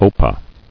[o·pah]